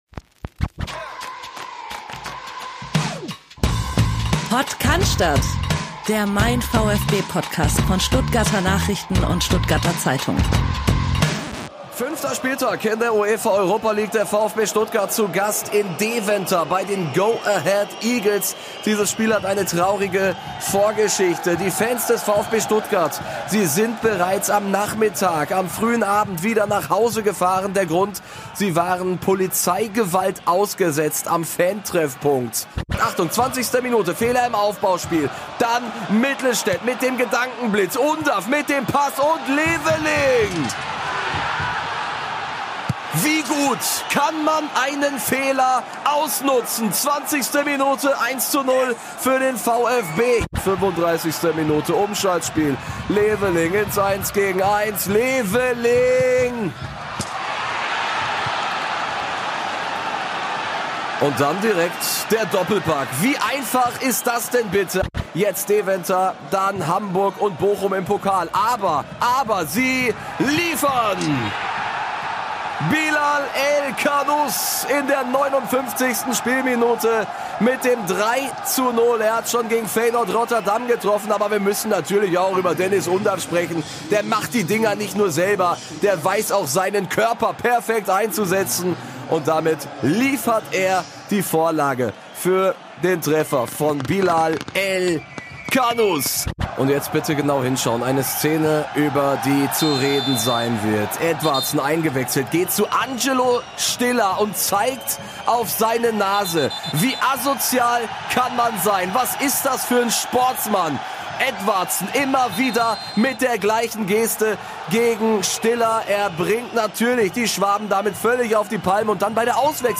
Professionell, nah dran, kompakt aufbereitet - wöchentlich sprechen unsere VfB-Reporter über den VfB Stuttgart, seine aktuelle Form und die Themen, die die Fans bewegen.